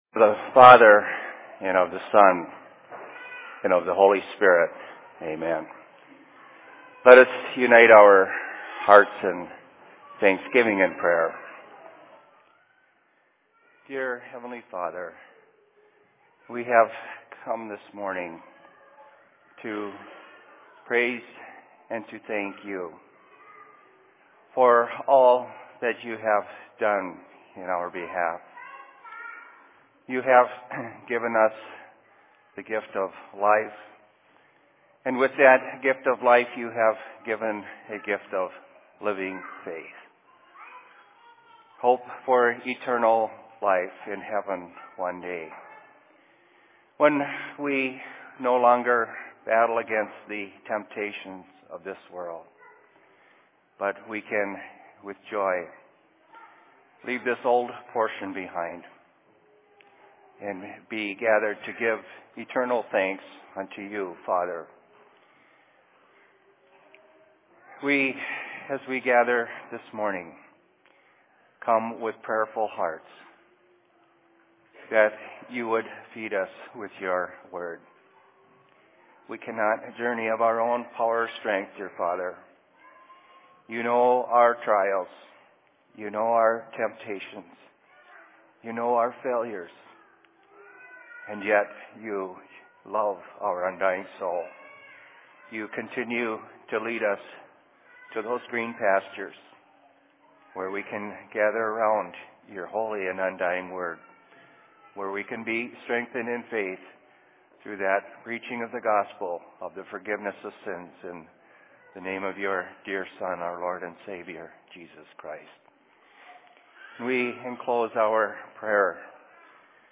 Sermon in Rockford 26.02.2012
Location: LLC Rockford